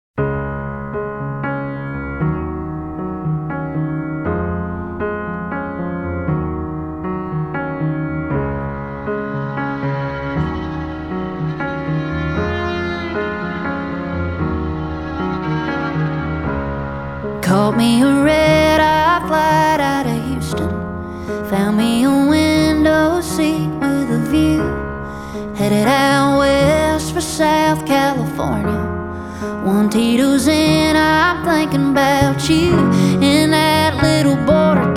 Country
Жанр: Кантри